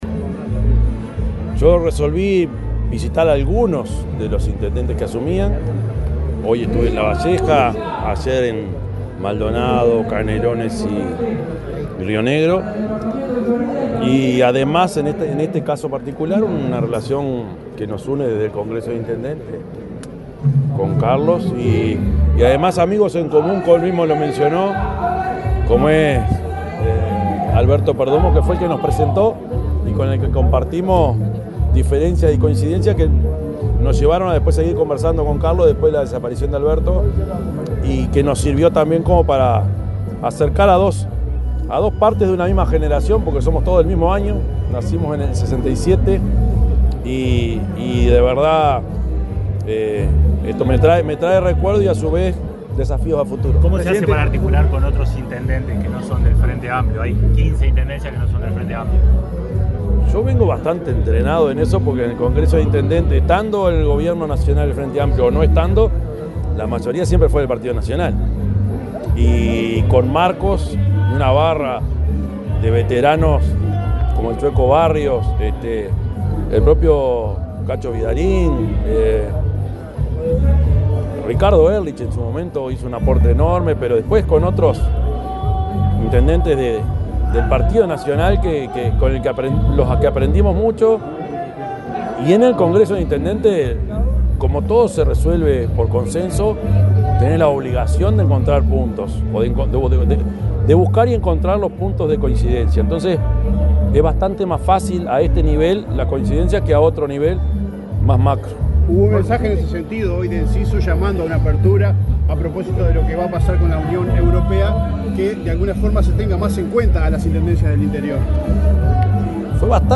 Declaraciones del presidente de la República, Yamandú Orsi, en Florida
Tras la asunción del intendente de Florida, Carlos Enciso, el presidente de la República, Yamandú Orsi, realizó declaraciones a la prensa.